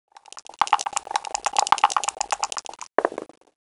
Würfeln
Hierbei handelt es sich um ein Geräusch, welches viele Brett- und alle Würfelspiele begleitet. Es ist das klassische Geräusch zweier Würfel, die in einem eigens angefertigten Würfelbecher, welcher mit Filz gefüttert ist geschüttelt und anschließend auf einen Tisch gewürfelt werden.
wuerfeln